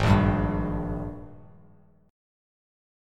G#M7sus2 chord